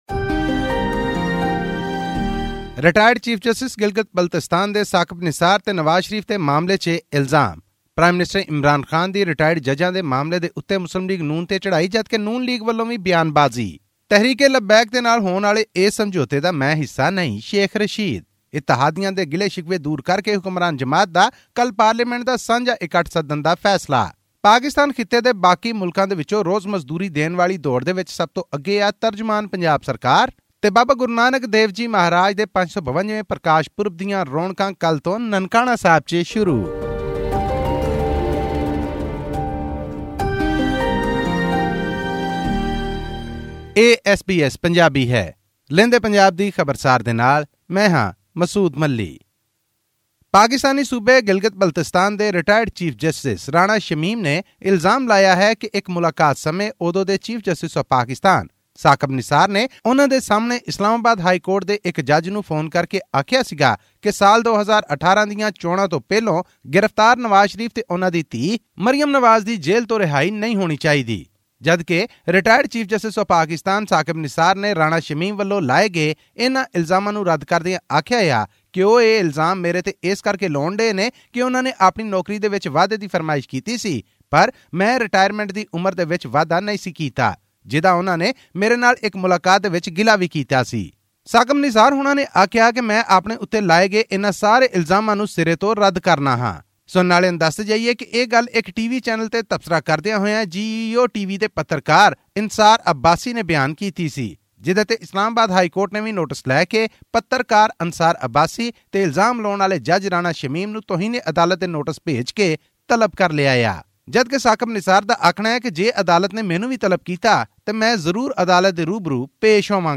Pakistan High Commission has issued about 3,000 visas to Indian Sikh pilgrims to enable them to participate in the 552nd birth anniversary celebrations of Guru Nanak in Pakistan from 17 to 26 November. This and more in our weekly news update.